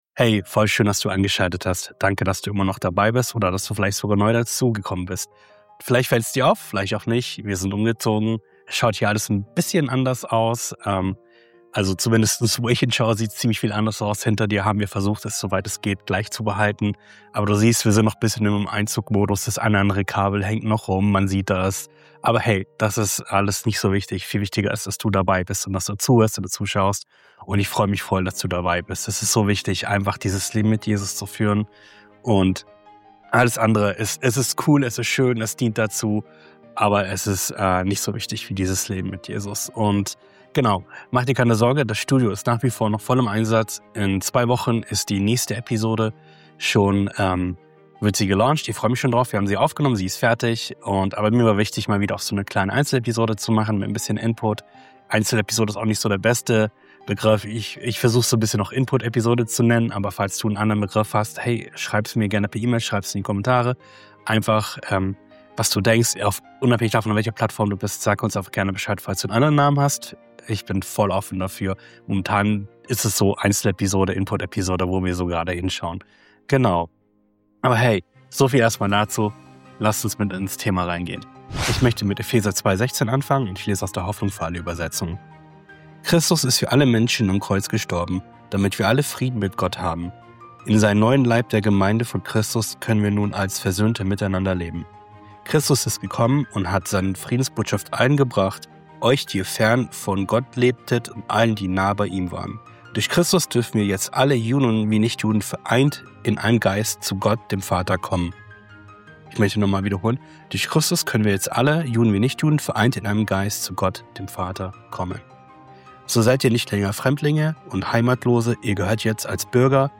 In dieser Einzelepisode spreche ich über den Zugang zu Gott und darüber, warum Nähe zu ihm weniger eine Frage von Leistung, sondern vielmehr eine Frage der inneren Haltung ist.